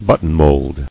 [but·ton·mold]